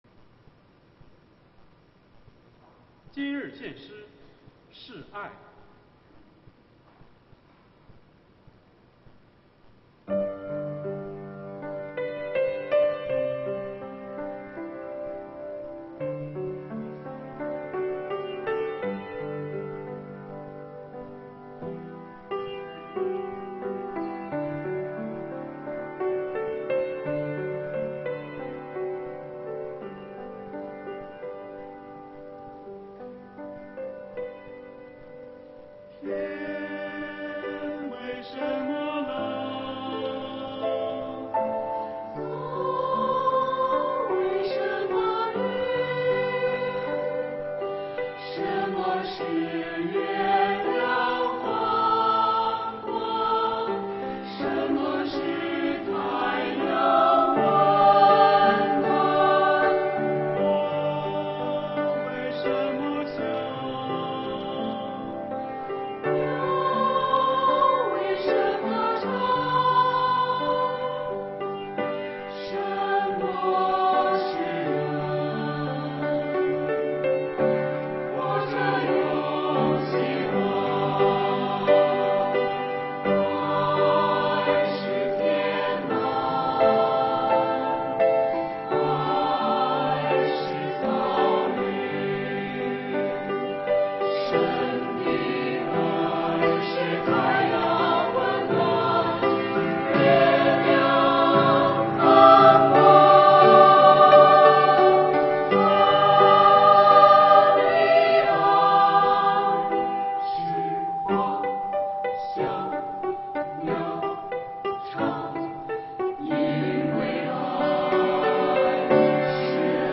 [2021年7月11日主日献唱]《是爱》 | 北京基督教会海淀堂
团契名称: 青年诗班
诗班献诗